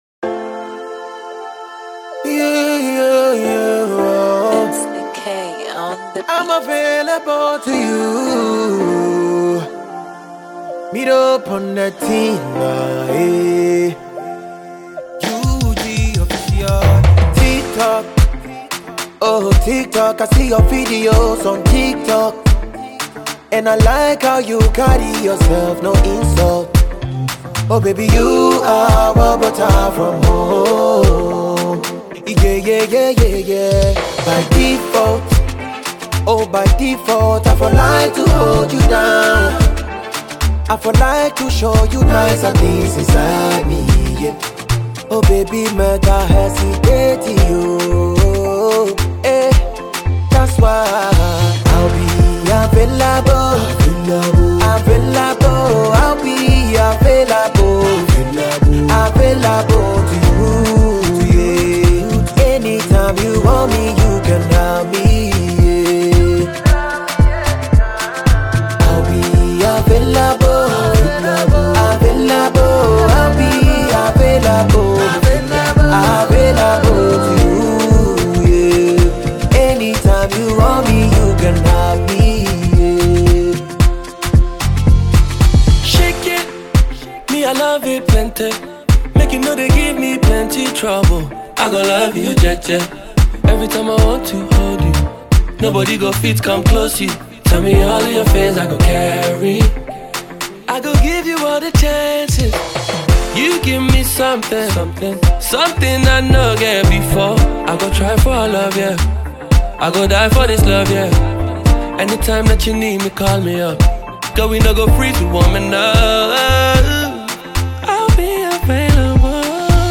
This energetic masterpiece